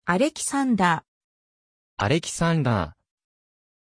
Aussprache von Alexandr
pronunciation-alexandr-ja.mp3